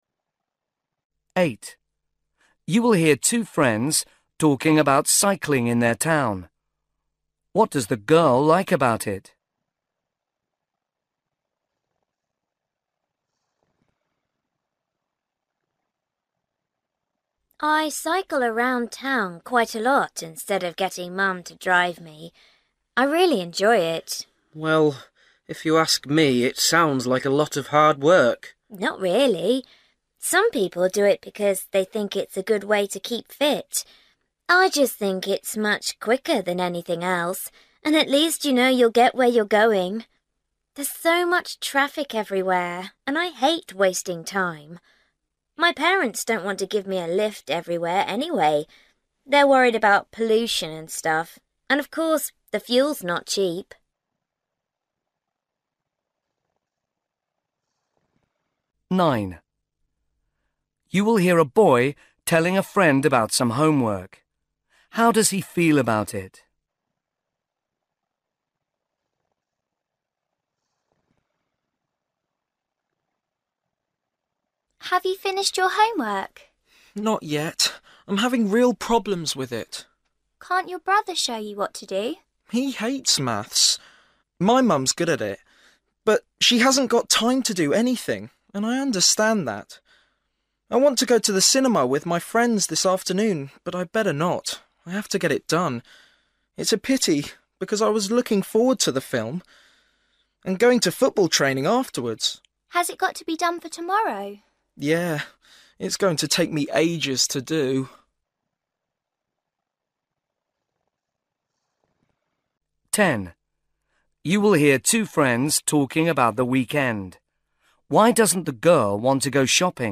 8   You will hear two friends talking about cycling in their town.
9   You will hear a boy telling a friend about some homework.
10   You will hear two friends talking about the weekend.
12   You will hear a girl telling a friend about a hockey match she played in.